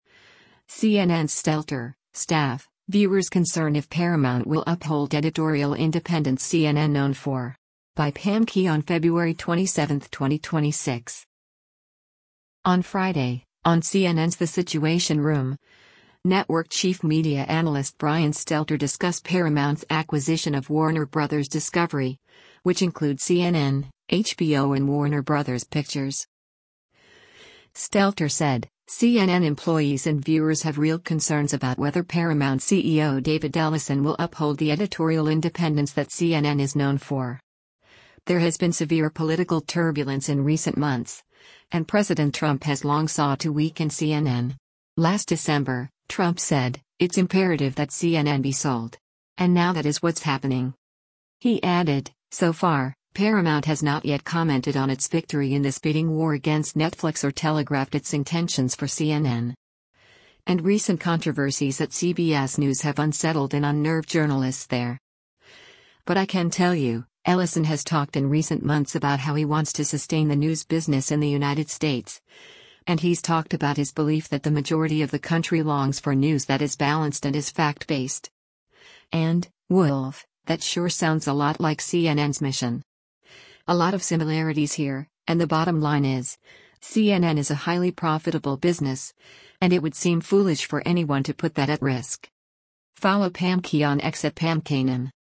On Friday, on CNN’s “The Situation Room,” network chief media analyst Brian Stelter discussed Paramount’s acquisition of Warner Bros. Discovery, which includes CNN, HBO and Warner Bros. Pictures.